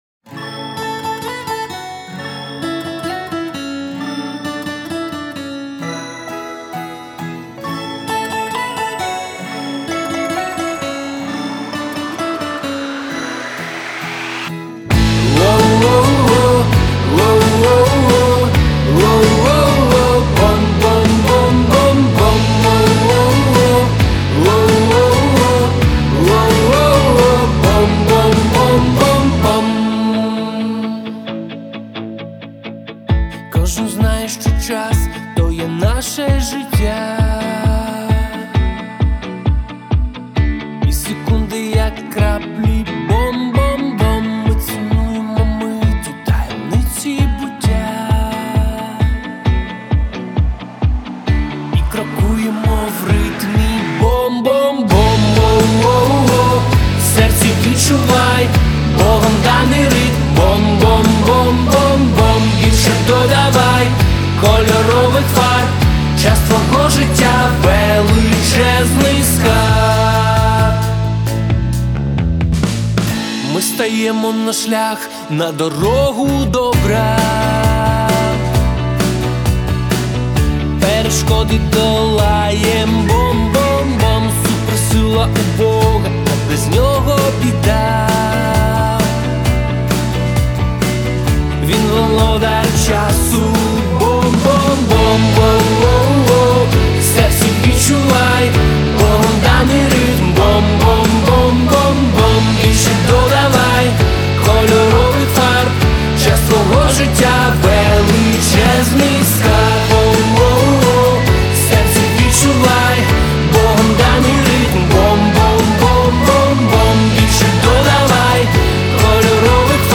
396 просмотров 572 прослушивания 67 скачиваний BPM: 132